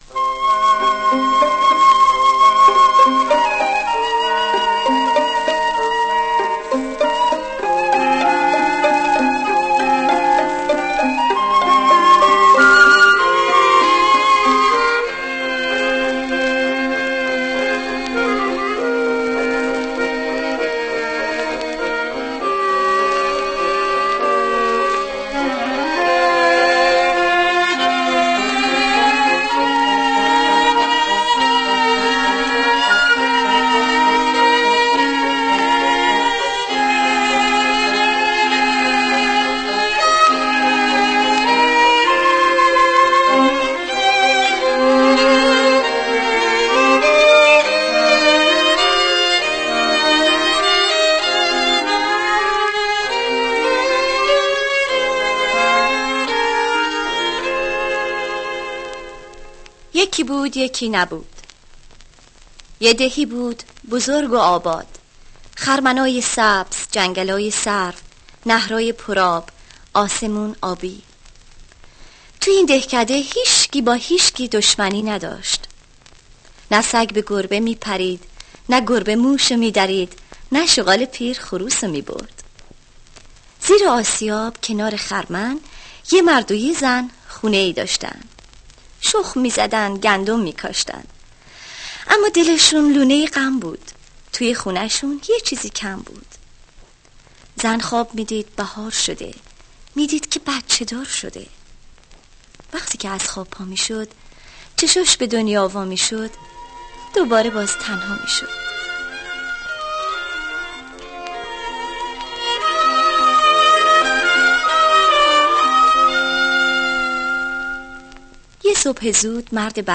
قصه کودکانه صوتی قهرمان